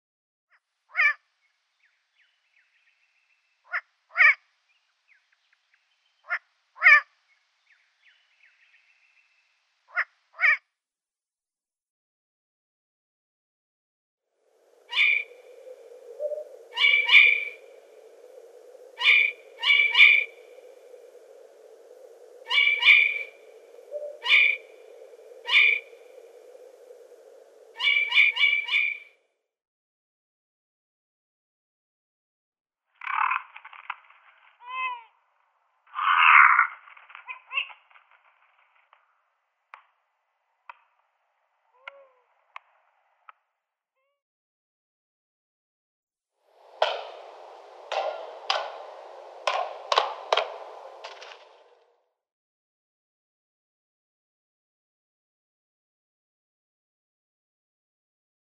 نام فارسی: جغد گوش‌دراز
نام علمی: Asio otus
20b.Long-eared Owl.mp3